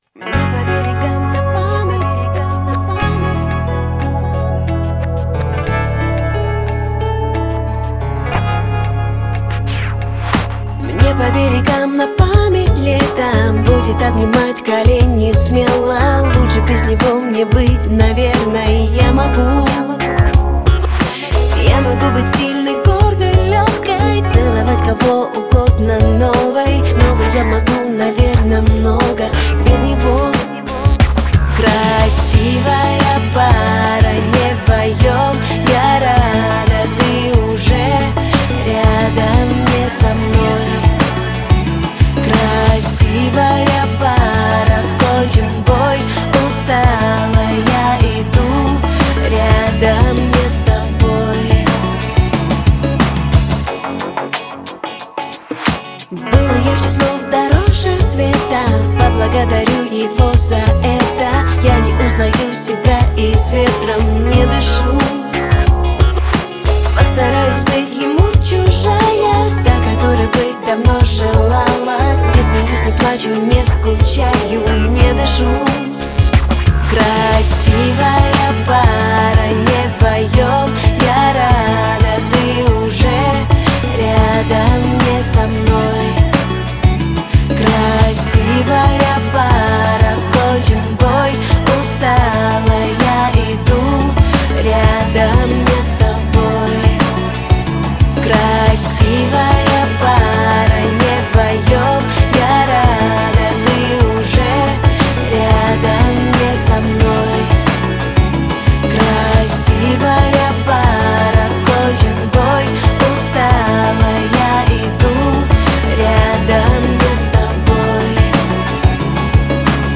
Главная » Популярная музыка